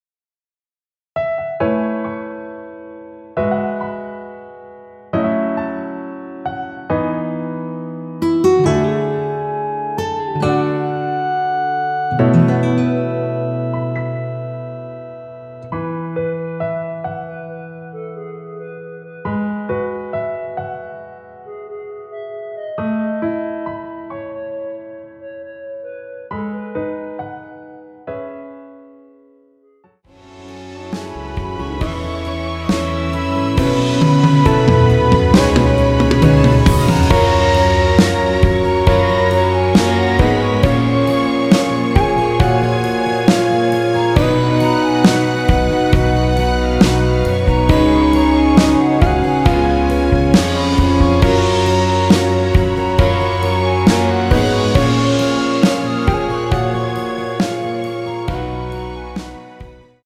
여성분이 부르실 수 있는 키로 제작하였습니다.(미리듣기 확인)
원키에서(+4)올린 멜로디 포함된 MR입니다.
앞부분30초, 뒷부분30초씩 편집해서 올려 드리고 있습니다.